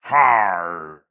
Minecraft Version Minecraft Version snapshot Latest Release | Latest Snapshot snapshot / assets / minecraft / sounds / mob / pillager / celebrate4.ogg Compare With Compare With Latest Release | Latest Snapshot
celebrate4.ogg